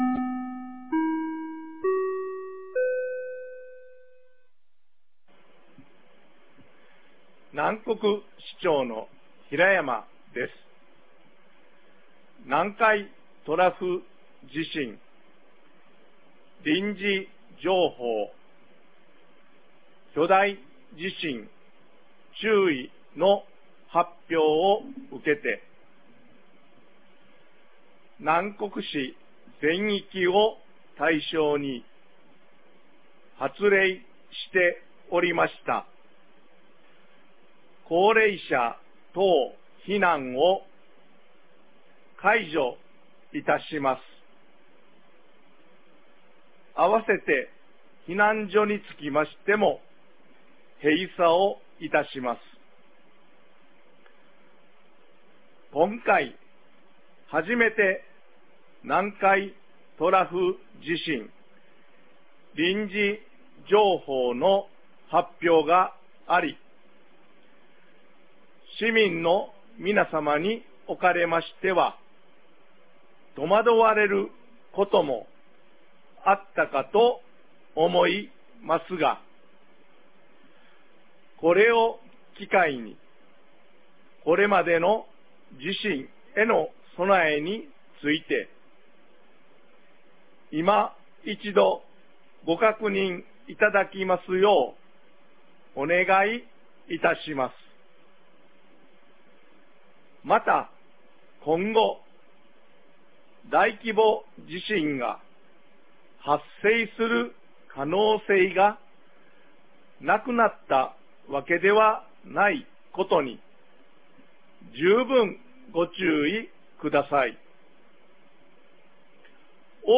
2024年08月15日 17時24分に、南国市より放送がありました。